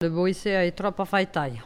Localisation Perrier (Le)
Catégorie Locution